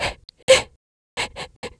Ripine-Vox_Sad_kr.wav